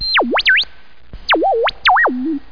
1 channel
06121_Sound_Radio.mp3